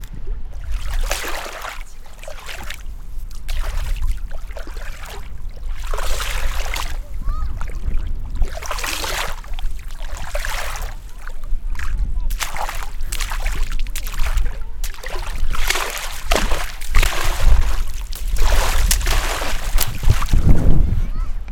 물장구.mp3